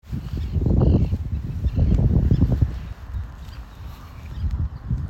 Birds -> Terns ->
Common Tern, Sterna hirundo